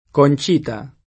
vai all'elenco alfabetico delle voci ingrandisci il carattere 100% rimpicciolisci il carattere stampa invia tramite posta elettronica codividi su Facebook Conchita [sp. kon ©& ta ] pers. f. (= Concetta) — adattam. it.